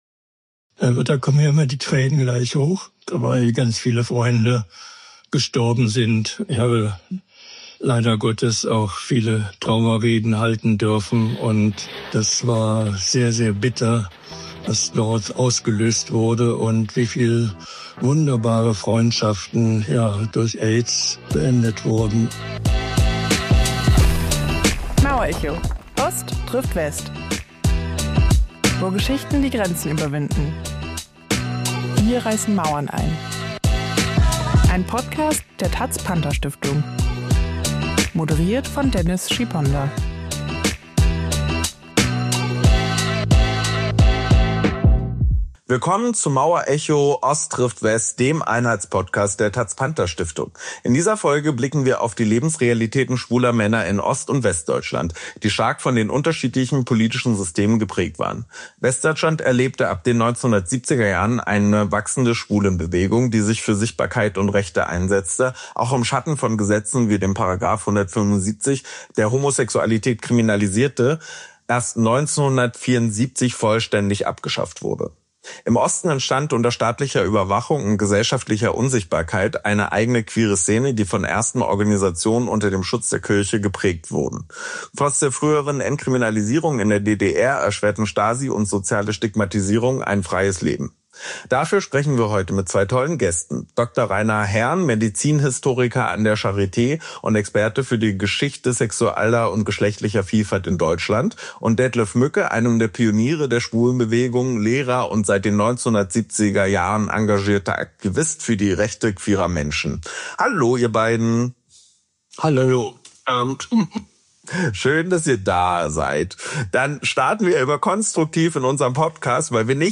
Im Podcast sprechen sie über das queere Leben in Ost und West, über Kriminalisierung und juristische Verfolgung und die AIDS-Epidemie in den 80er-Jahren. Aber es geht auch um politischen Kampf, Selbstermächtigung und den Aufbau einer queeren Kultur.